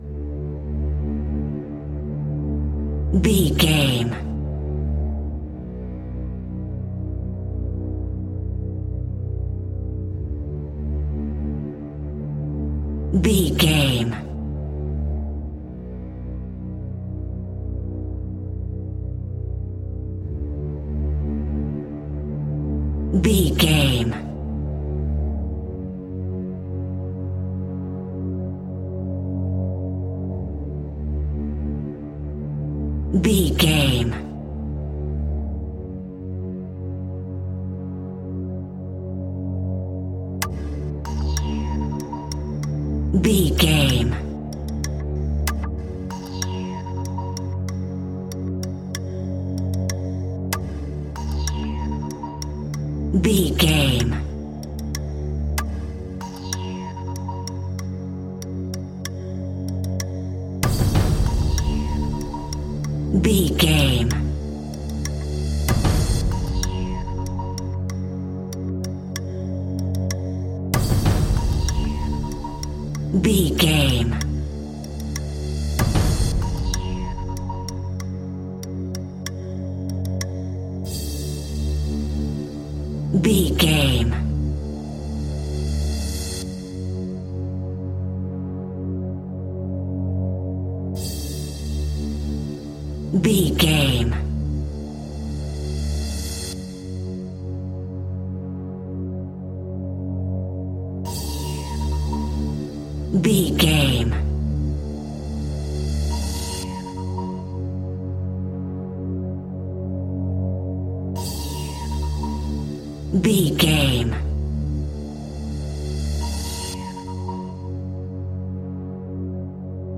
Aeolian/Minor
ominous
dark
suspense
haunting
eerie
industrial
cello
synthesiser
percussion
drums
horror music
horror instrumentals